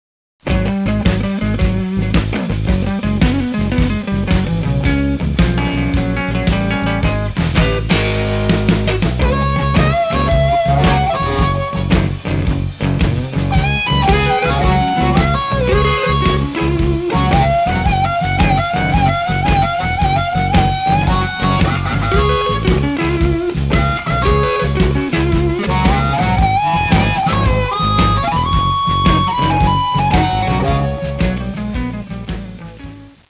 Electric and Acoustic Guitar
Bass
Drums
Piano